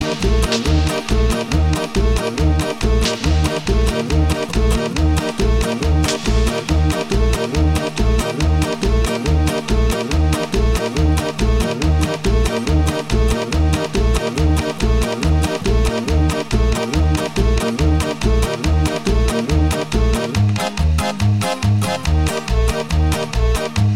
Country (Male)